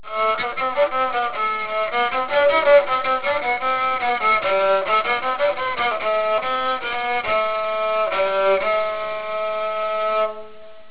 REBEC
Rebec Sound Clips
It is played with a simple horsehair bow and makes a distinctive rasping sound.